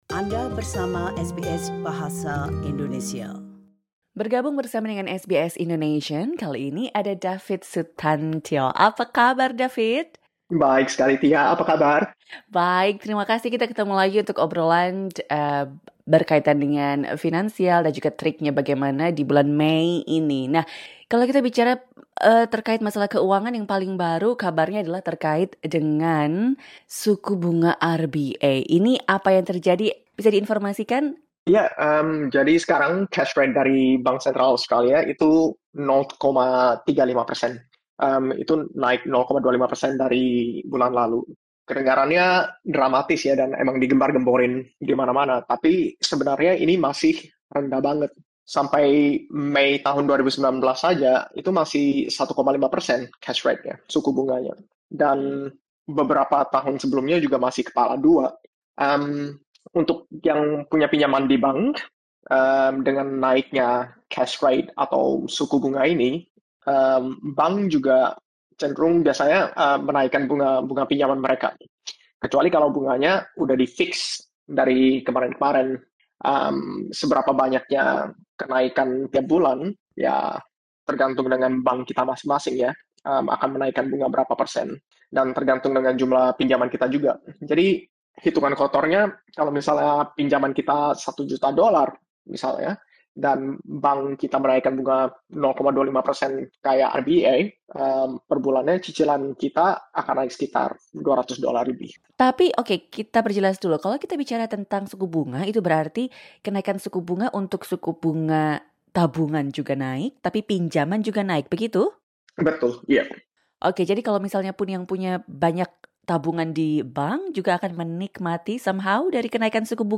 Dengarkan wawancara selengkapnya di sini: LISTEN TO Suku Bunga Naik, Kita Perlu Panik?